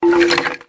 snd_summonskulls.ogg